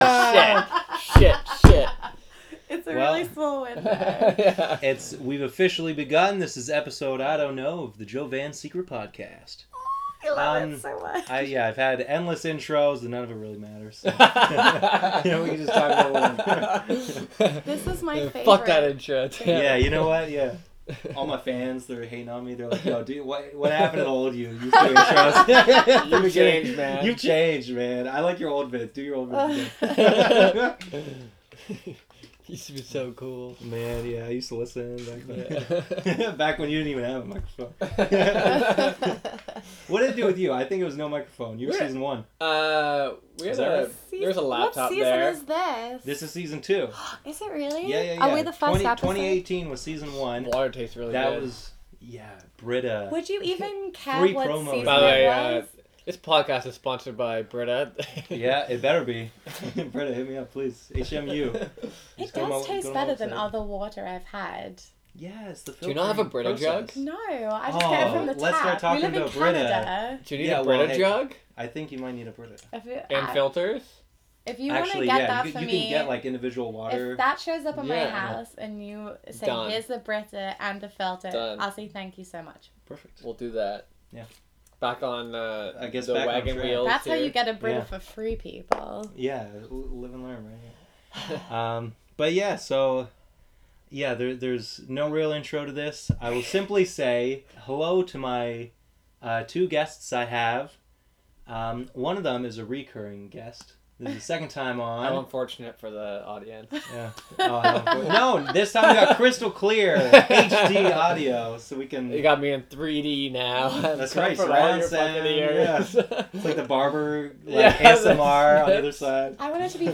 I talk with two amazing people at the end of my first LSD experience. We discuss shows we were raised with, outdated metro-sexuality, taking advantage of the social market, magic mushrooms, k-holes, Macbeth, LOVE, women's rights, and my own little book plug.